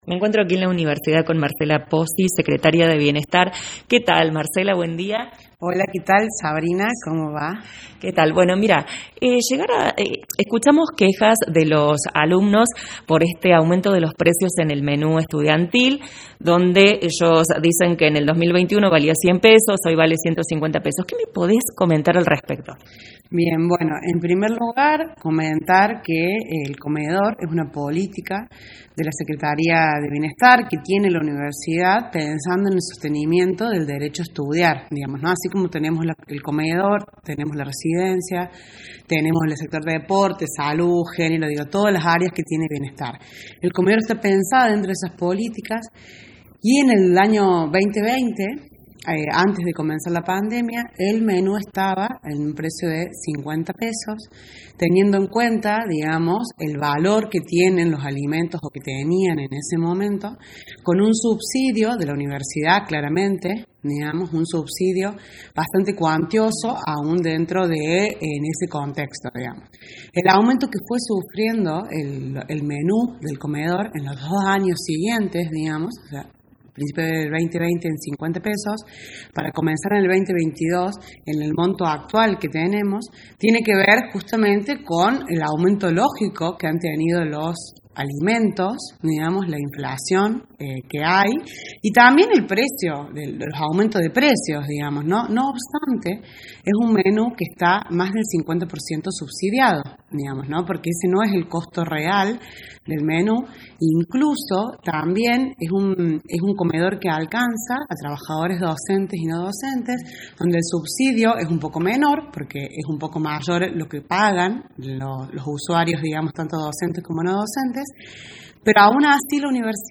Desde la Secretaría de Bienestar de la Universidad Nacional de Villa María nos comentan, en «La Mañana Informal», al respecto del aumento de los precios en el menú estudiantil que pasó a valer de $100 (precio anterior a la pandemia) a $150 dos años después.
Alumnas de la UNVM también se refirieron a la falta de líneas de colectivos además del menú para los estudiantes.